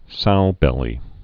(soubĕlē)